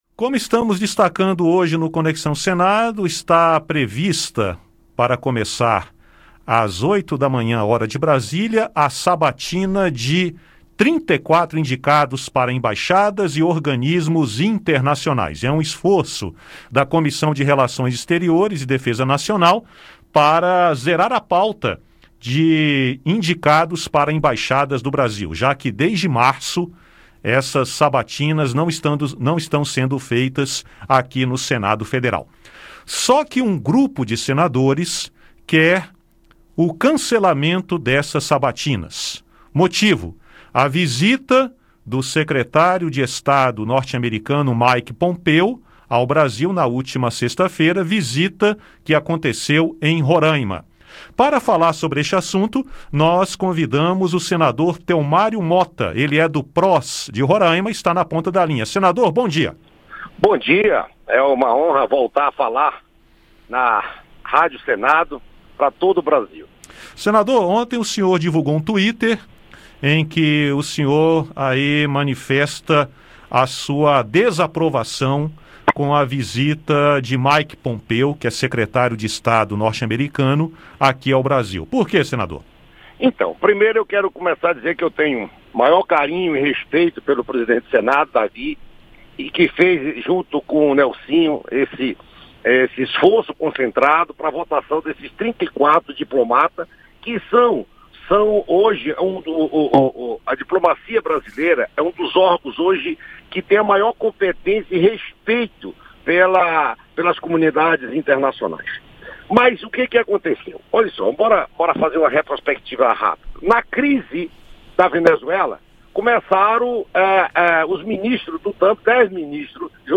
Entrevista: Telmário acusa Estados Unidos de criar conflito entre Brasil e Venezuela